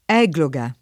$gloga] s. f. — lett. ecloga [$kloga]: fiorisce delle innovate bellezze delle ècloghe ed elegie antiche [fLor&šše delle innov#te bell%ZZe delle $kloge ed eleJ&e ant&ke] (Carducci) — con ecl- la forma originaria, dal gr. ἐκλογή / ekolgé [eklog$] «scelta», lat. ecloga [$kloga]; con egl- una forma dovuta a una falsa etimologia mediev. e rimasta domin., per i poemetti bucolici di Virgilio, anche in fr. (églogue) e in sp. (égloga) — solo Ecloga come tit. di alcuni testi di diritto bizantino